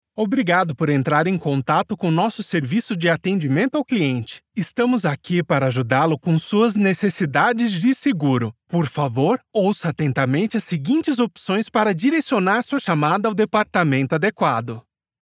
Ma configuration de studio à la pointe de la technologie garantit une qualité sonore exceptionnelle pour chaque projet.
Cabine acoustique parfaite